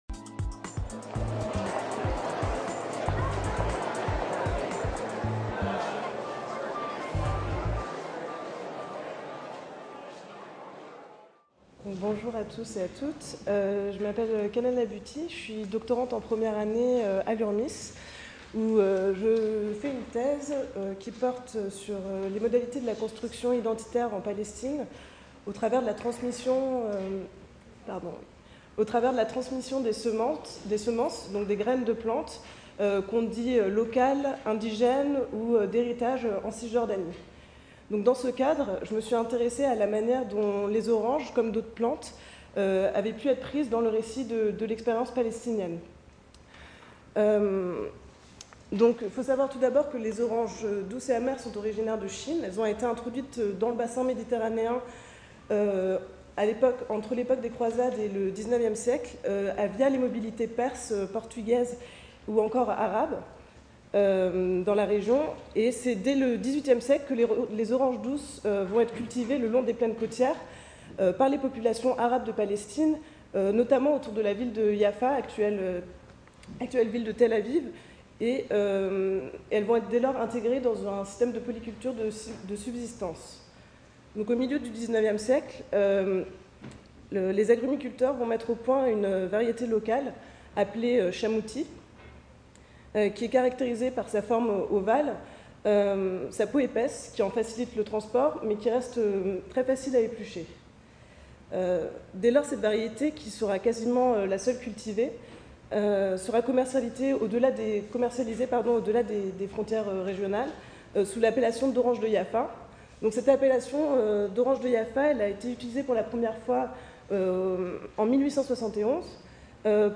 Jeudi 28 mars 2019, Jardin des Plantes, Paris CIRCULATION DE VÉGÉTAUX DANS LE MONDE : LES TERRAINS DES CHERCHEURS